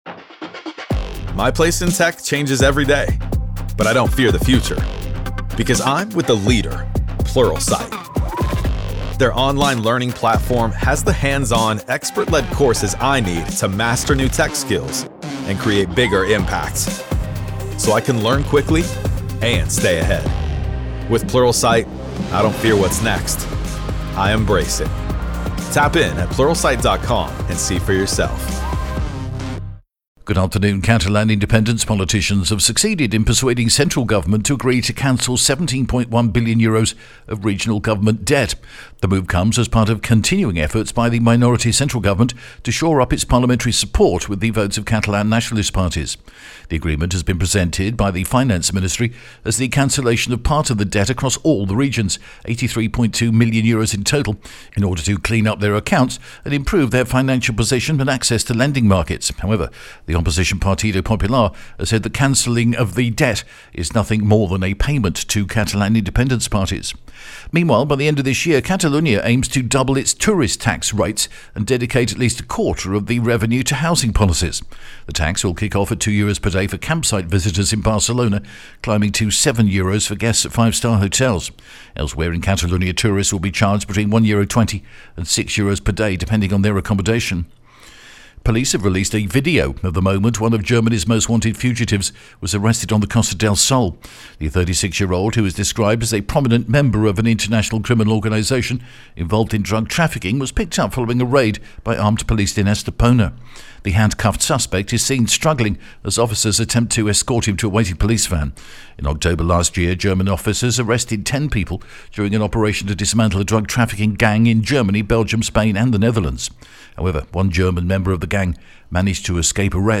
The latest Spanish news headlines in English: February 28th 2025